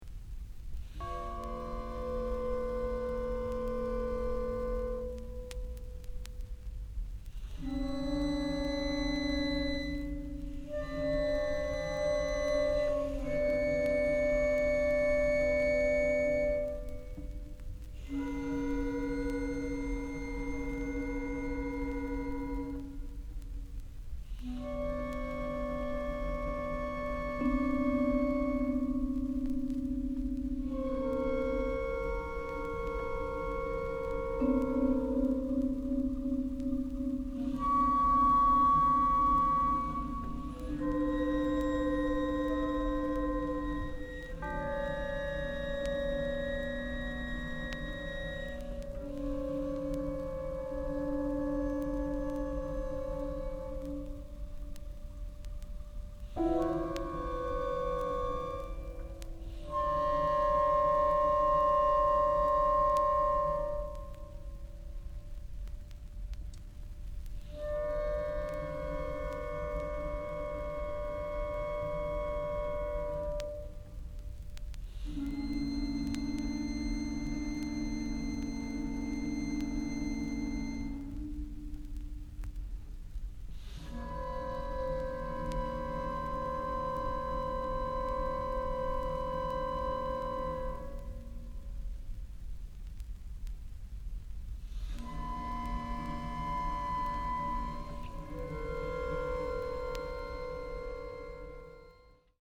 濃密な静寂の気配と揺らめく音色の移ろいに、森厳とした陰影を湛える礼拝堂の空間性が重なる珠玉のサウンドスケープ。
キーワード：　ミニマル　室内楽